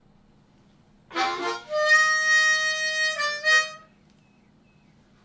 Bird Tweets
描述：Some bird chirps in a temperate forest in spring.
标签： forest bird tweet
声道立体声